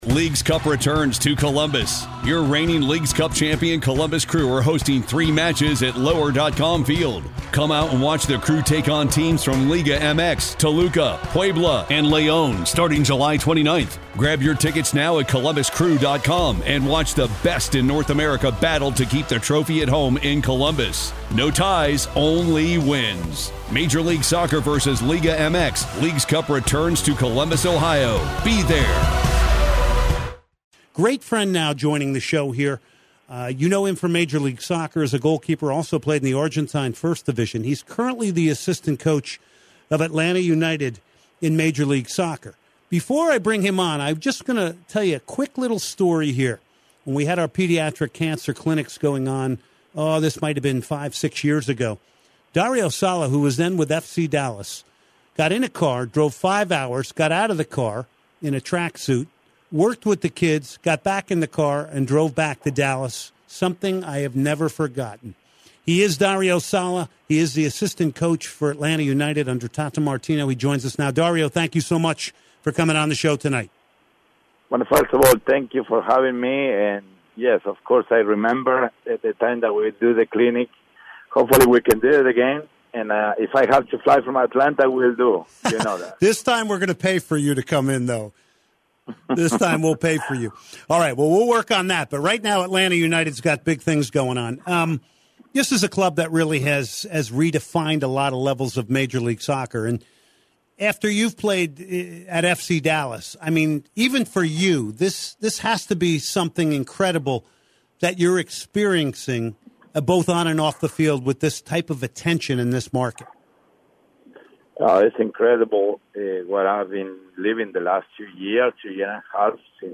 10/22/2018 Soccer Matters Interview